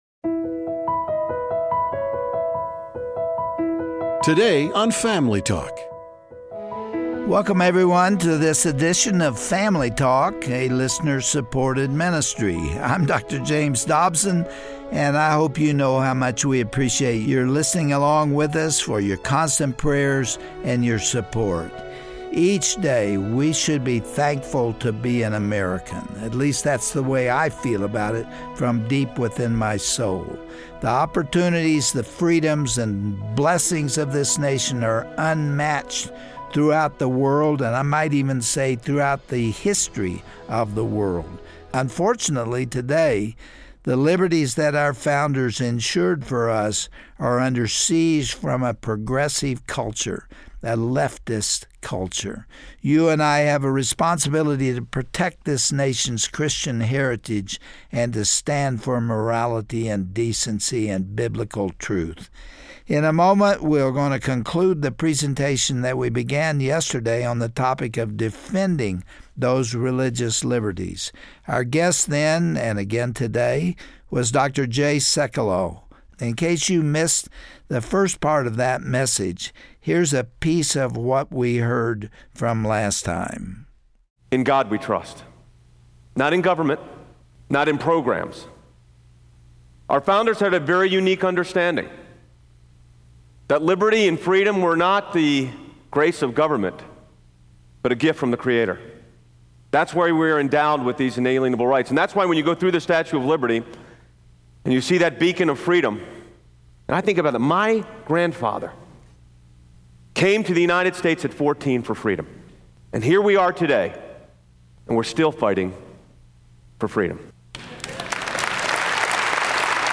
On this Family Talk broadcast, Dr. Jay Sekulow, from the American Center for Law and Justice, concludes his presentation on protecting this countrys liberties. He explains how we should approach unfairness, and why God honors our prayers for justice.